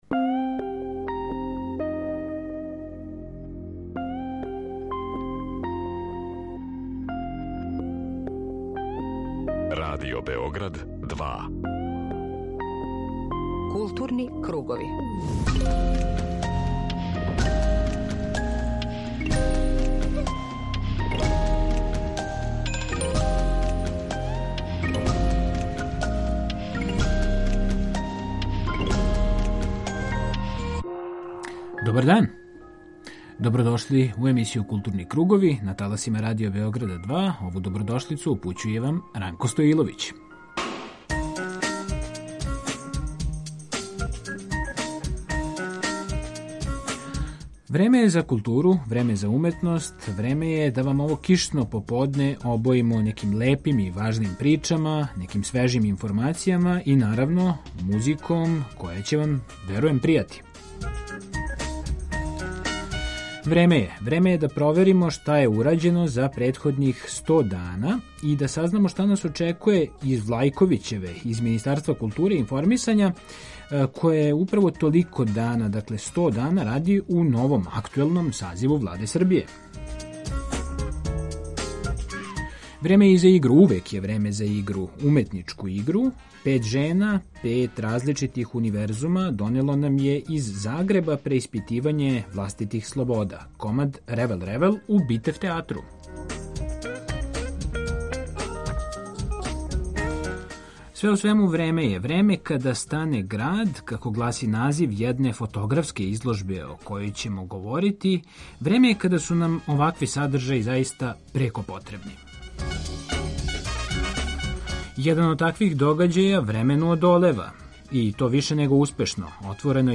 Сазнајемо како је протекло ово гостовање у Битеф театру. Пратимо и онлајн конференцију поводом 100 дана рада Министарства културе и информисања у новом сазиву Владе Србије.